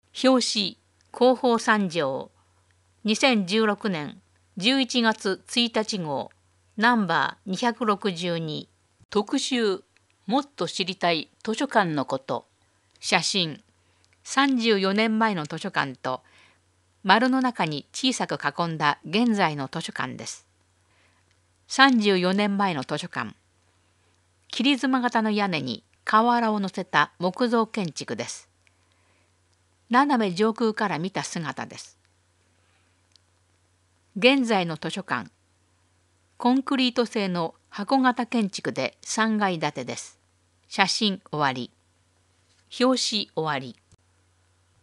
広報さんじょうを音声でお届けします。